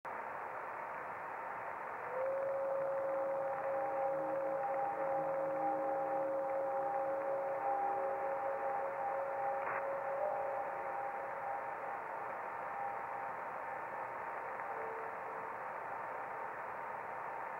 Radio spectrograph below shows forward scatter reflection during the 02:12 UT minute.